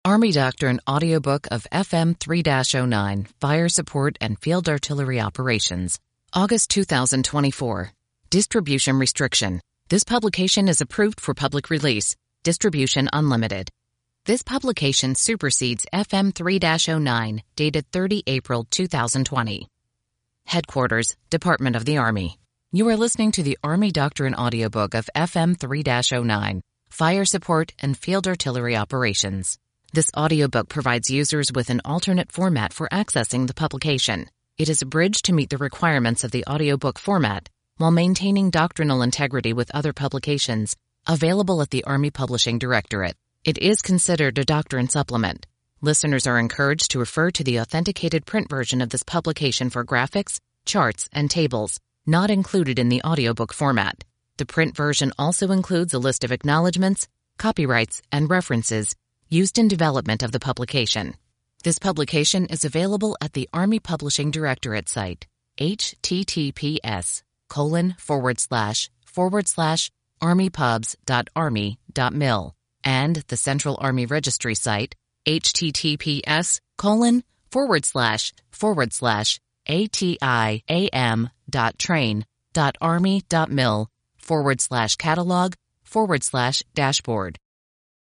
Army Doctrine Audiobook Download Page
It has been abridged to meet the requirements of the audiobook format.